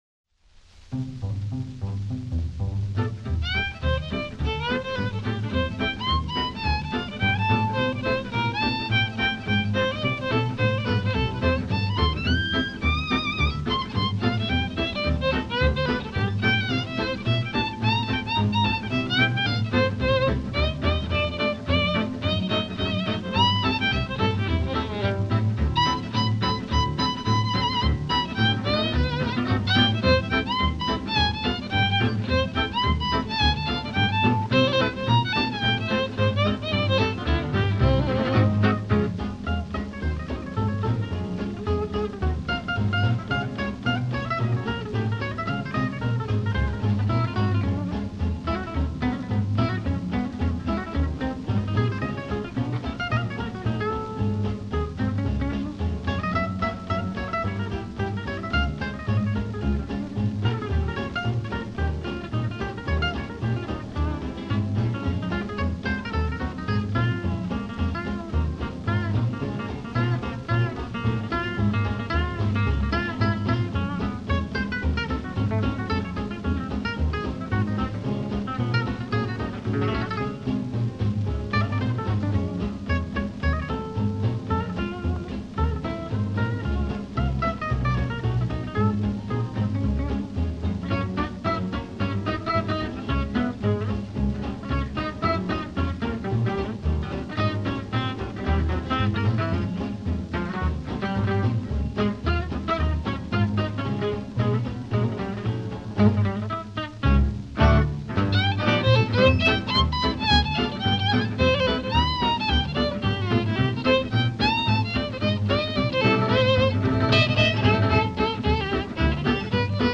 Swing_Guitars.mp3